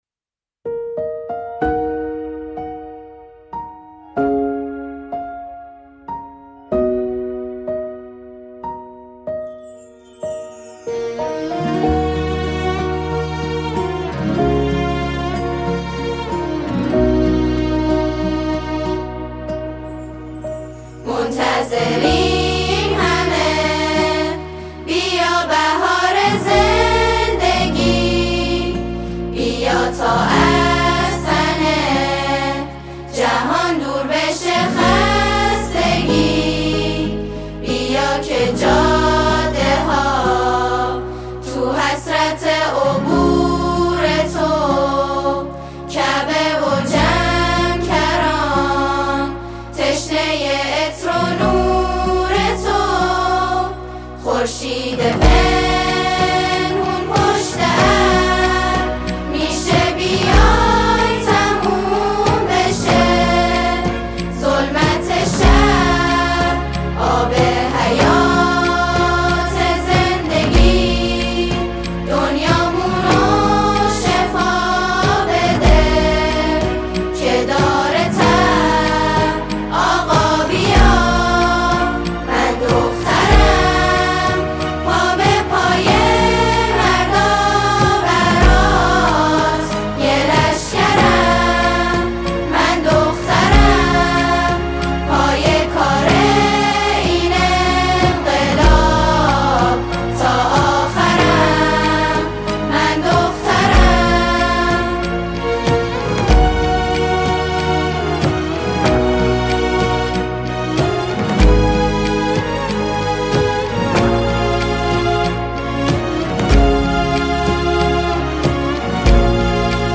اثری شنیدنی و پرشور